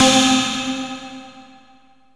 SYNTH GENERAL-1 0009.wav